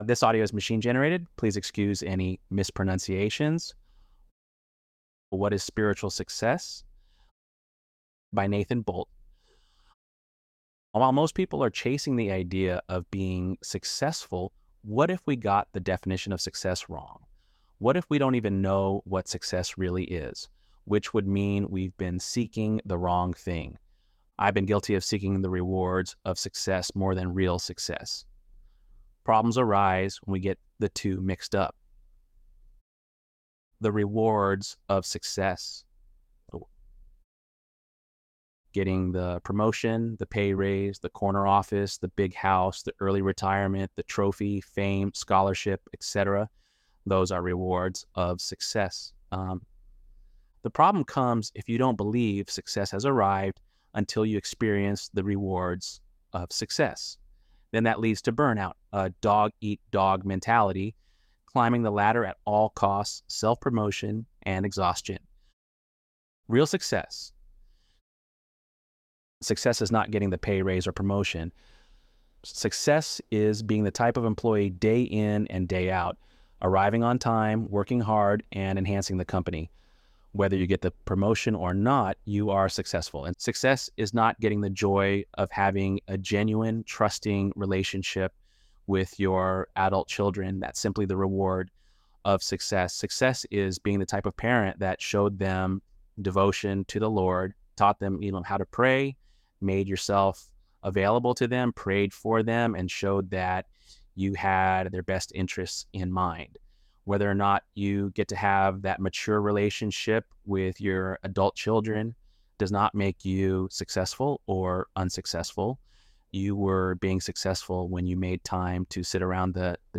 ElevenLabs_12_9.mp3